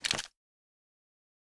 Weapon_Foley 03.wav